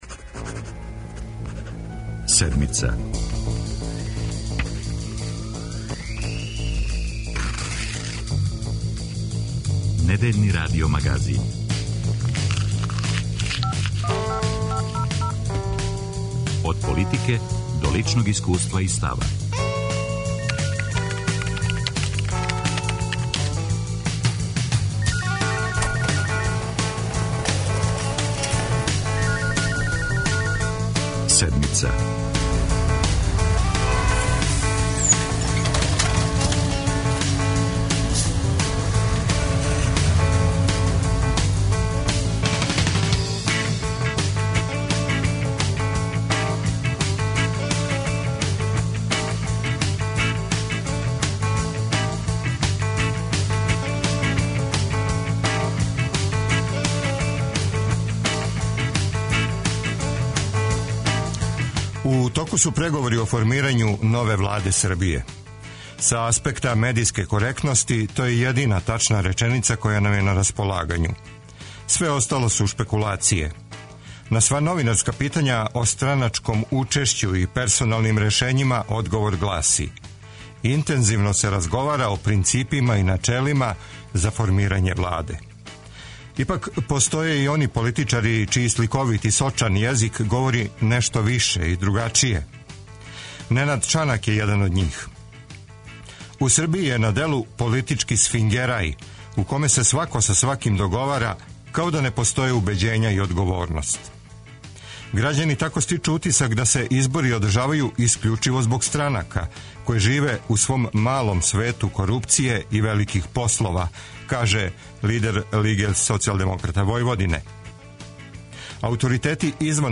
Каква нам Влада треба, шта су њени приоритети и колико је врућ кромпир који узимају у руке? За Седмицу говоре угледни економисти и чланови фискалног савета.